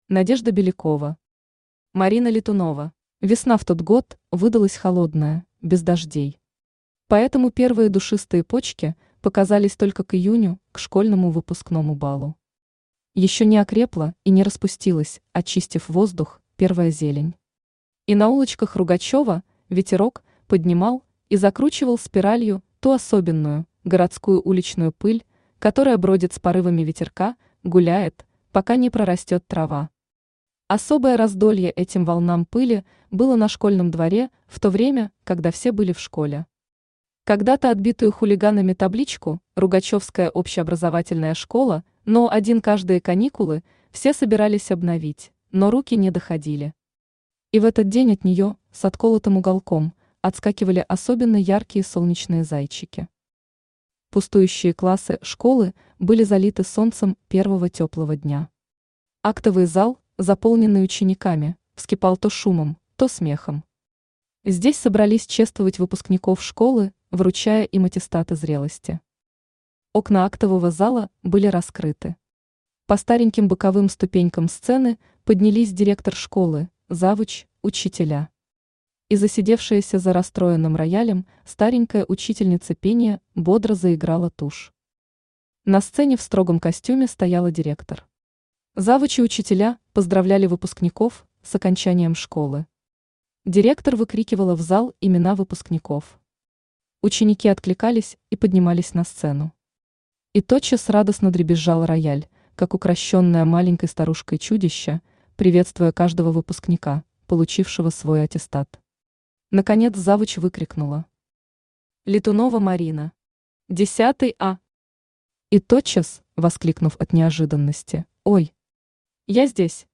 Aудиокнига Марина Летунова Автор Надежда Александровна Белякова Читает аудиокнигу Авточтец ЛитРес.